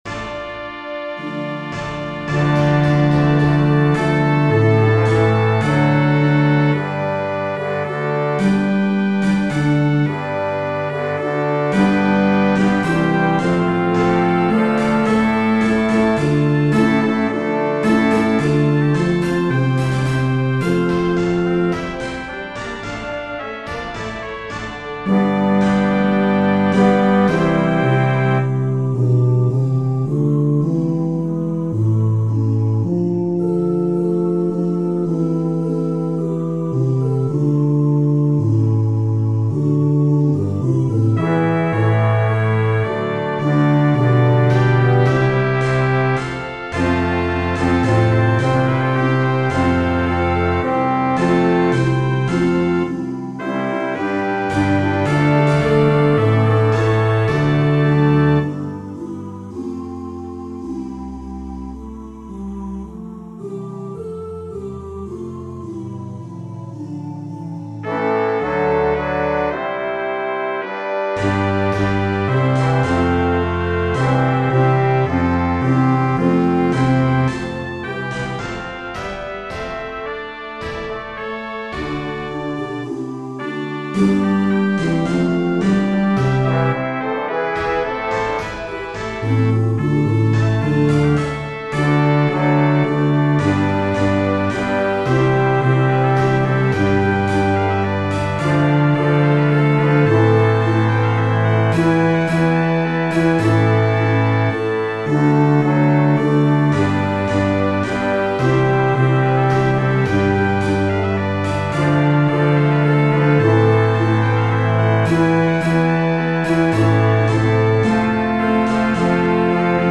All sixteen parts;  one featured voice:
These recordings have all 16 parts. The brass instruments have their own sounds. The human voices are “oh choir,” which sounds like we do when we sing on “doo.” The featured voice is a trumpet, trombone, or tuba. It is louder than the other parts.
Bass (Sounds like a tuba)
omnesgentesmod-bass.mp3